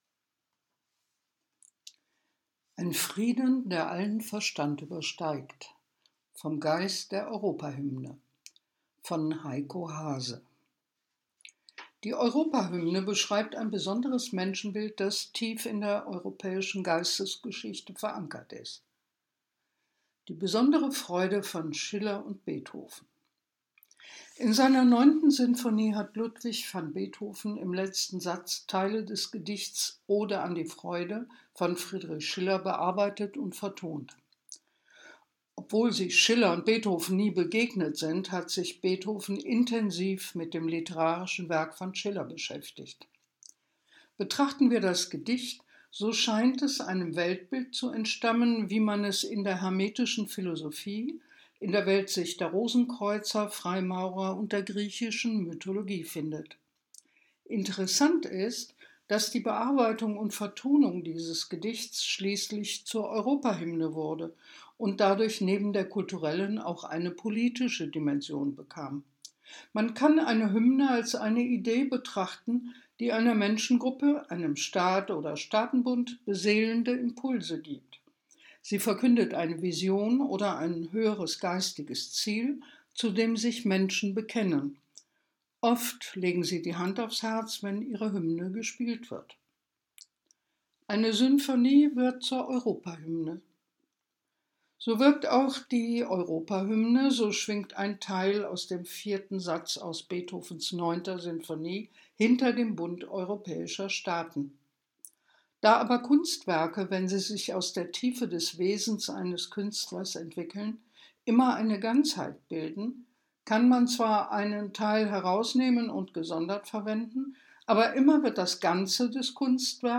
Artikel vorgelesen